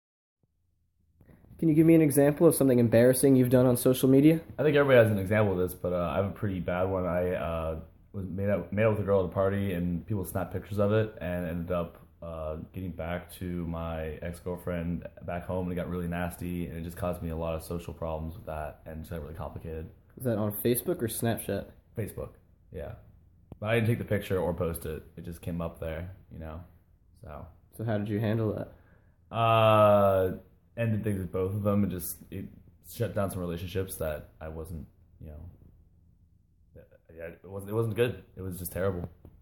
Student Speaks About Social Media Mistakes